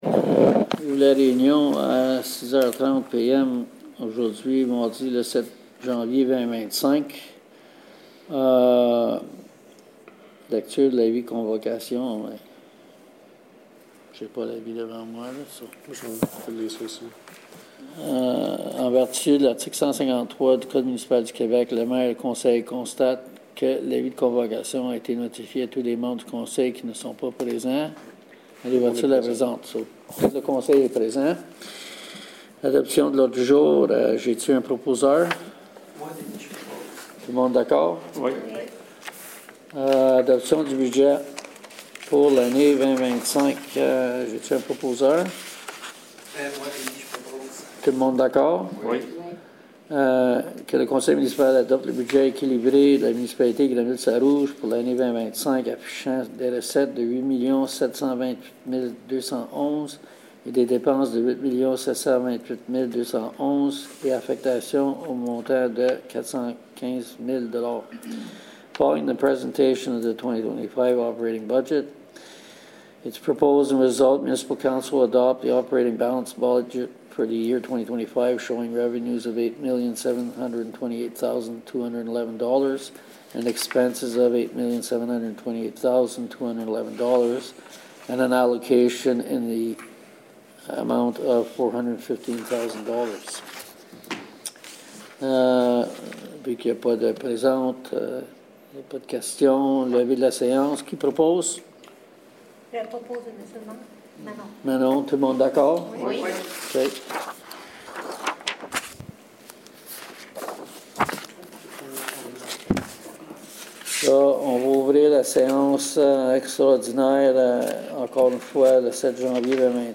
Special council meetings - Budget and three-year capital plan - January 7, 2025